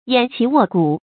偃旗臥鼓 注音： ㄧㄢˇ ㄑㄧˊ ㄨㄛˋ ㄍㄨˇ 讀音讀法： 意思解釋： 見「偃旗息鼓」。